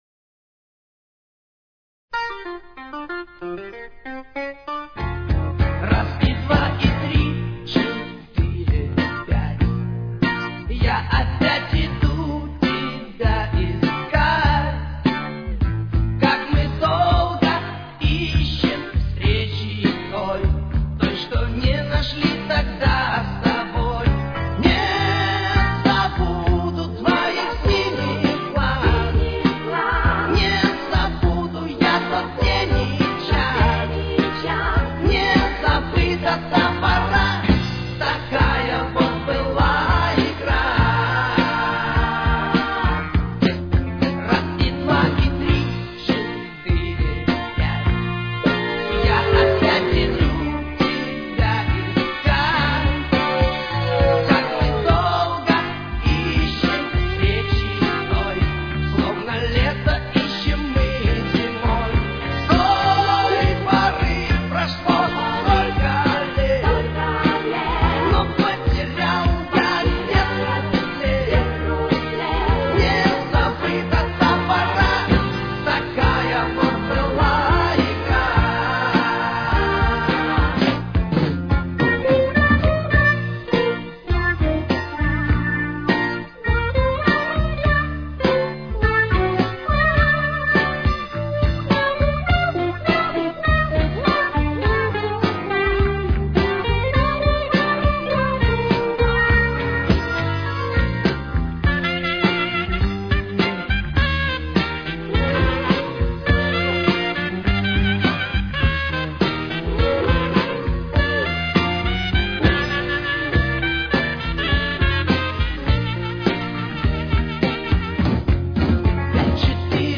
с очень низким качеством (16 – 32 кБит/с)
Тональность: Си-бемоль мажор. Темп: 103.